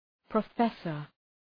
Προφορά
{prə’fesər} (Ουσιαστικό) ● καθηγητής